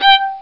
Fiddle Short(g) Sound Effect
Download a high-quality fiddle short(g) sound effect.
fiddle-short-g-2.mp3